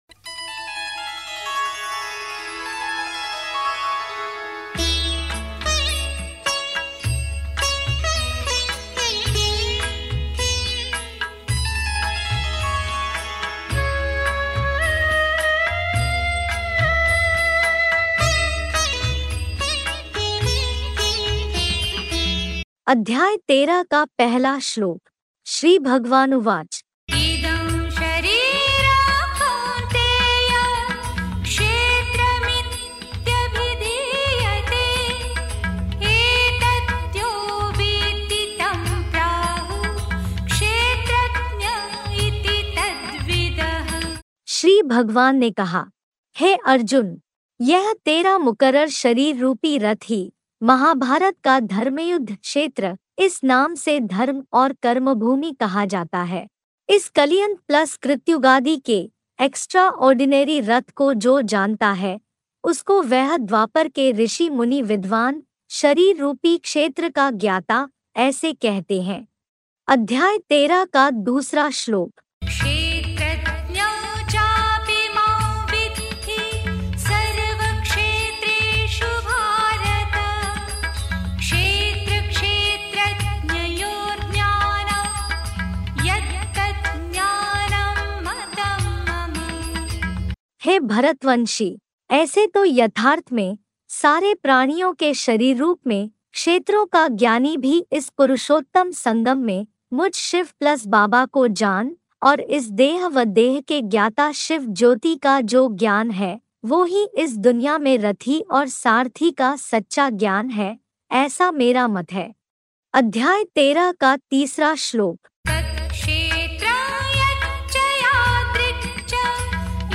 अध्याय -13 श्लोक उच्चारण